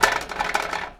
metal_rattle_spin_small_03.wav